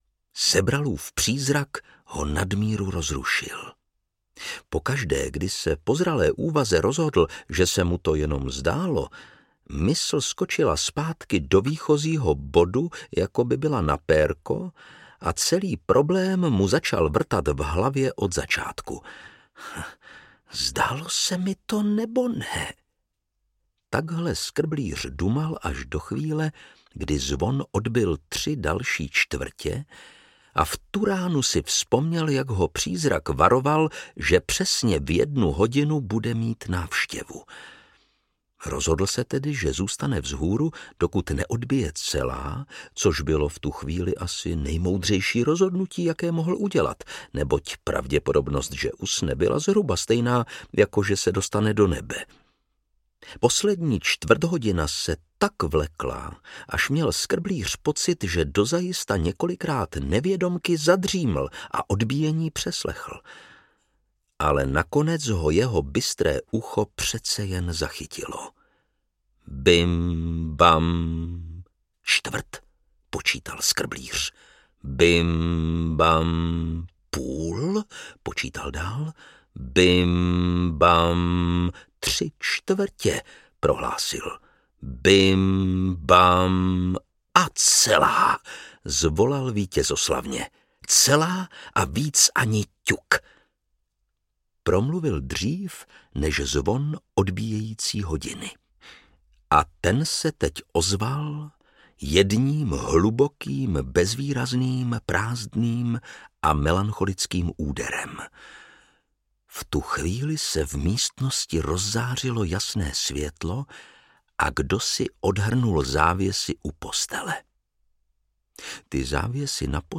Vánoční koleda audiokniha
Ukázka z knihy
• InterpretLukáš Hlavica